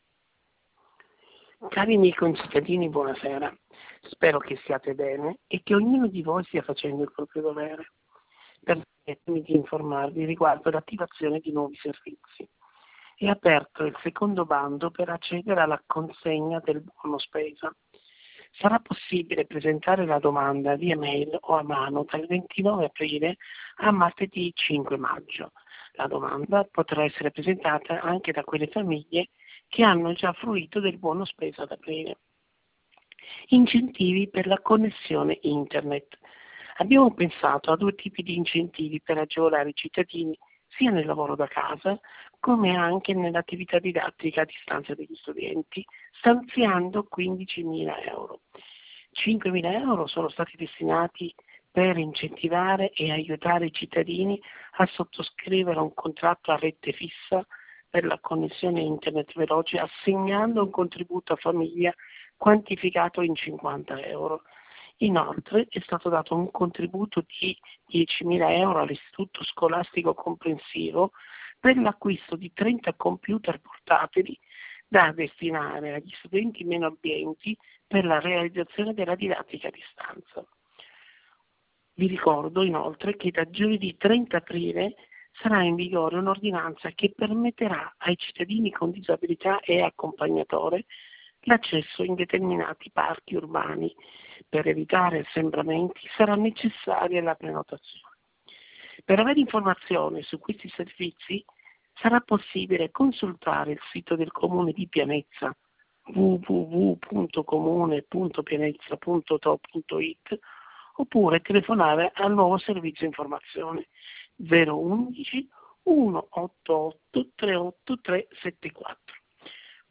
Messaggio telefonico del 28/04/2020